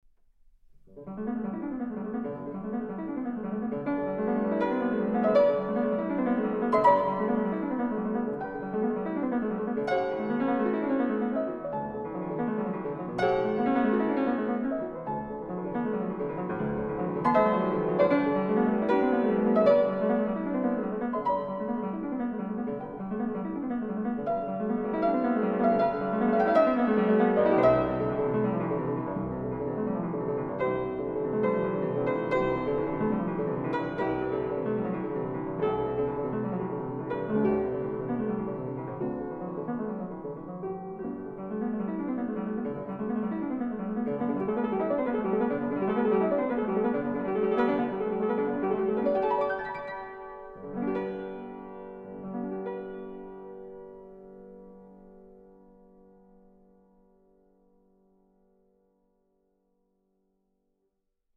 - nr 2 a-moll Lento